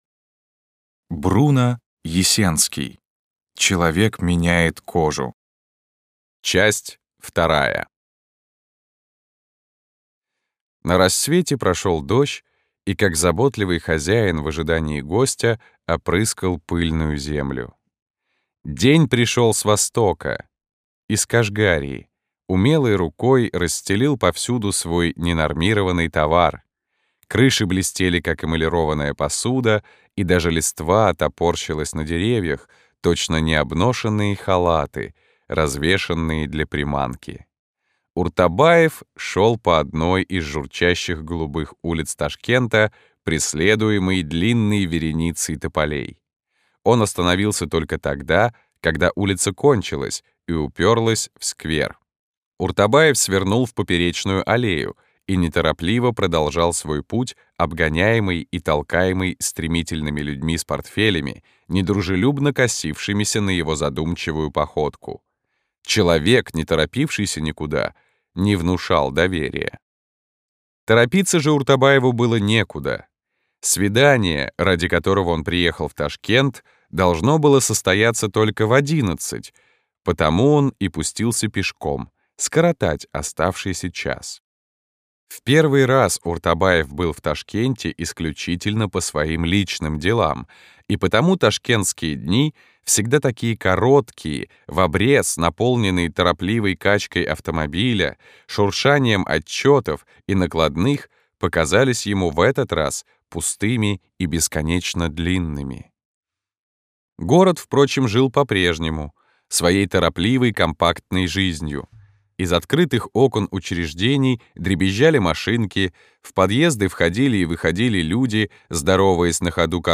Аудиокнига Человек меняет кожу. Часть 2 | Библиотека аудиокниг